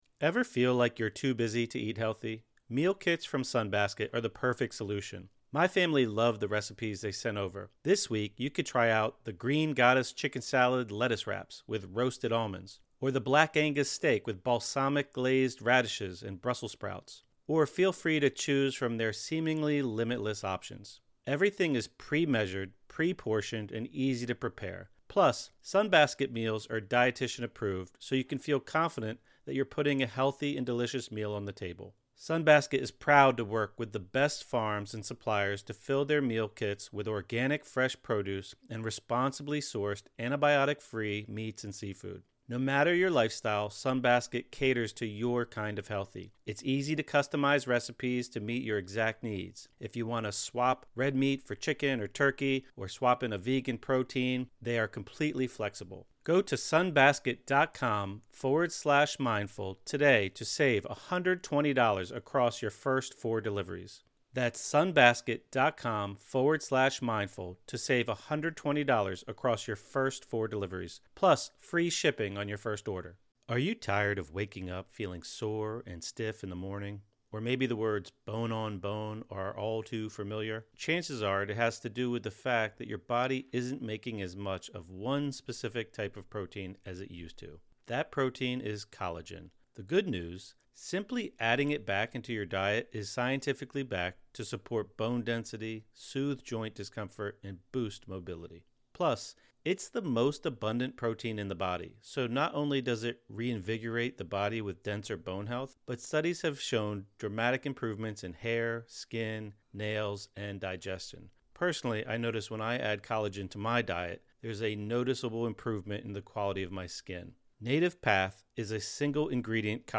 15 Minute Guided Meditation | Cultivate an Attitude of Gratitude (; 17 Nov 2024) | Padverb